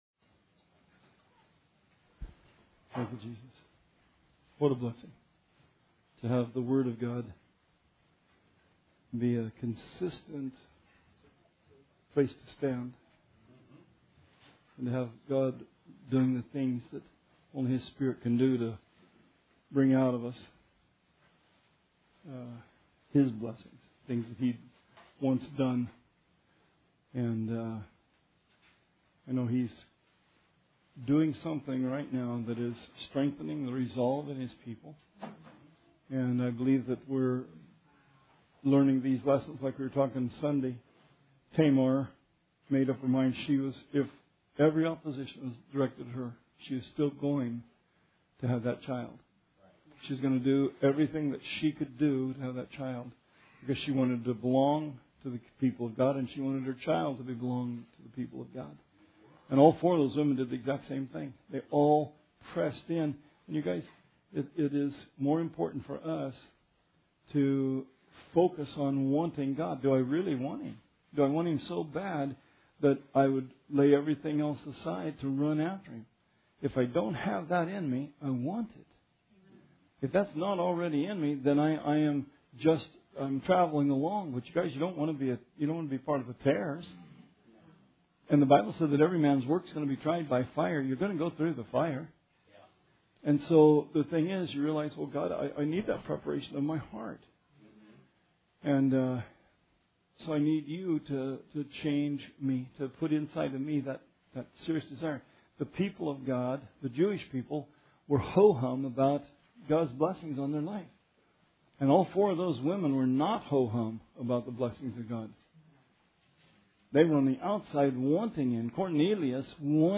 Bible Study 12/14/16